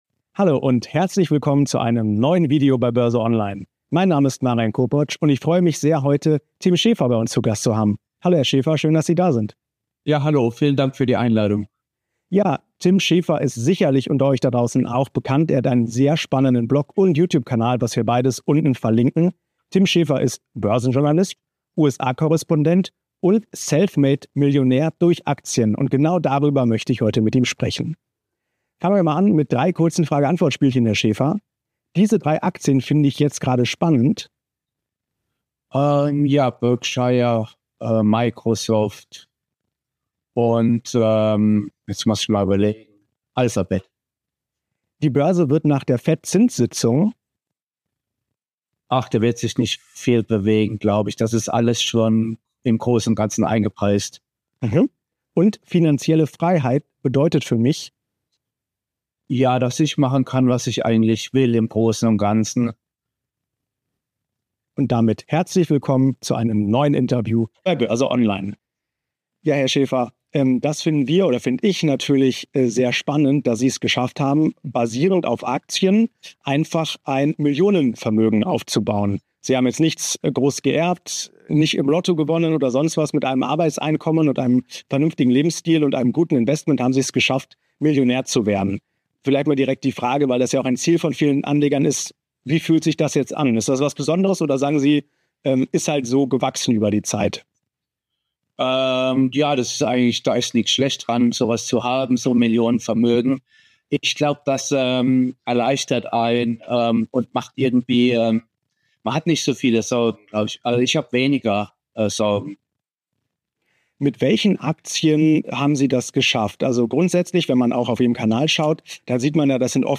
Deswegen sollte man keine Aktien verkaufen. BÖRSE ONLINE im Talk